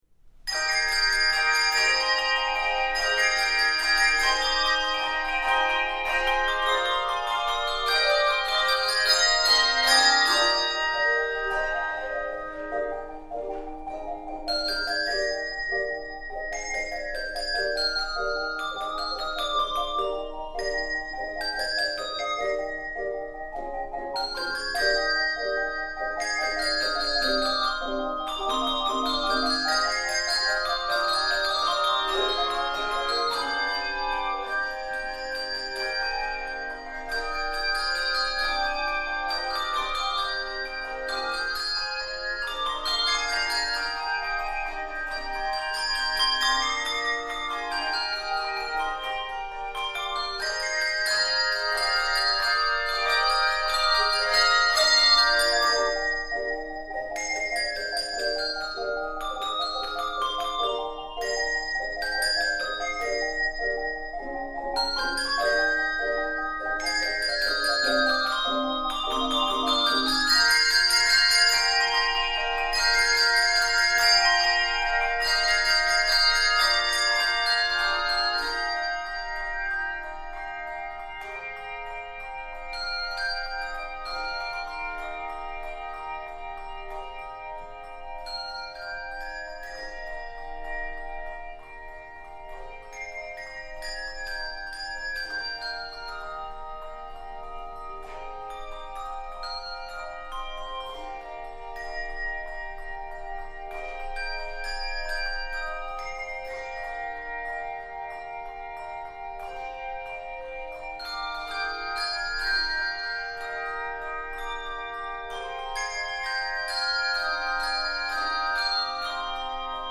Voicing: Handbells 3 Octave